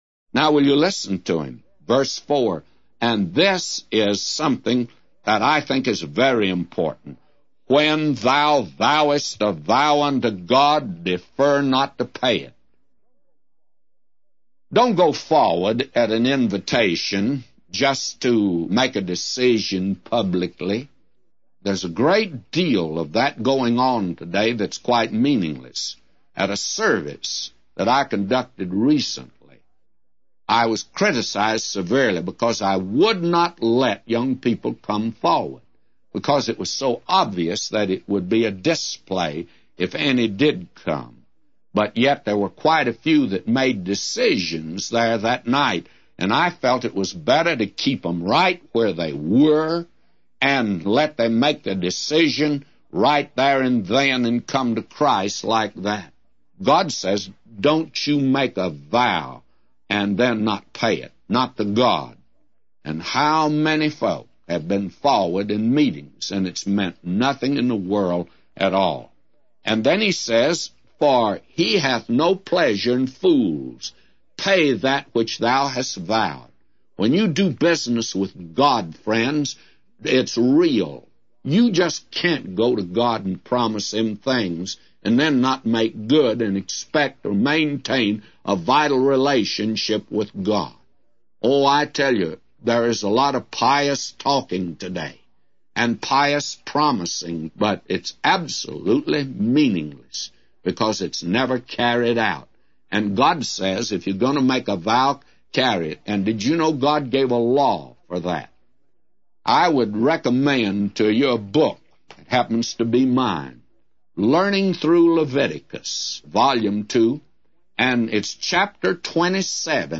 A Commentary By J Vernon MCgee For Ecclesiastes 5:4-999